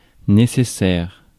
Ääntäminen
IPA : /ˈmæn.də.t(ə)ɹi/
IPA : /ˈmæn.dəˌtɔ.ɹi/